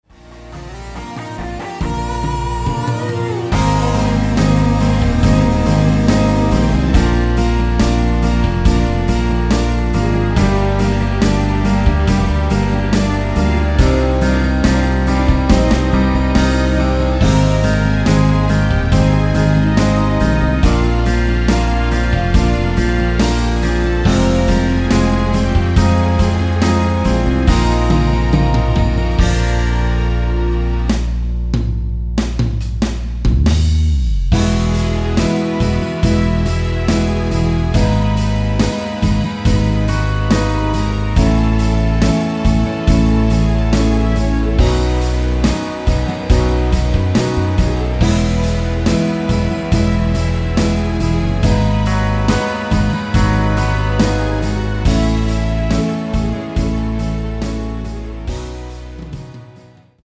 엔딩이 너무길고페이드 아웃이라 라이브 하시기 좋게 4마디로 편곡 하였습니다.
Eb
◈ 곡명 옆 (-1)은 반음 내림, (+1)은 반음 올림 입니다.
앞부분30초, 뒷부분30초씩 편집해서 올려 드리고 있습니다.
중간에 음이 끈어지고 다시 나오는 이유는